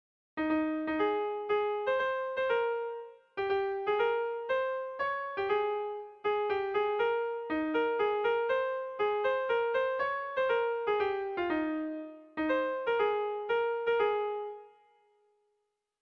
Bertso melodies - View details   To know more about this section
Irrizkoa
ABDE